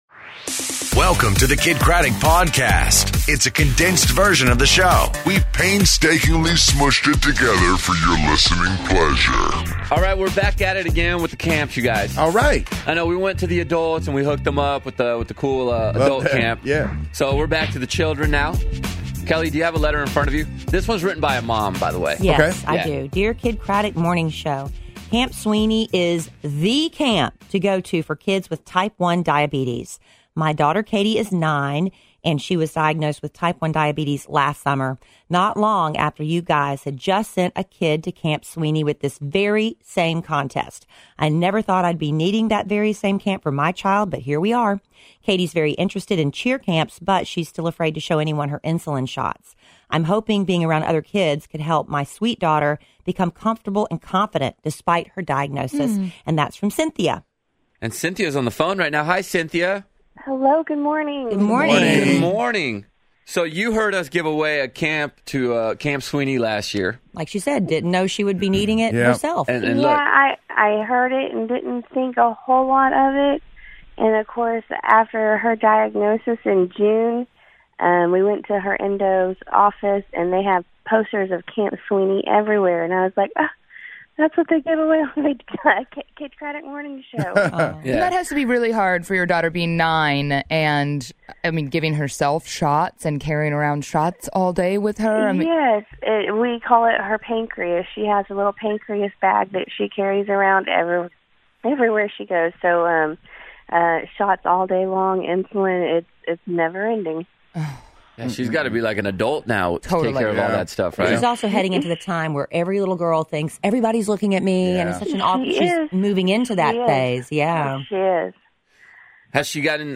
Harry Styles on the phone... we did other stuff too but you only care about Harry Styles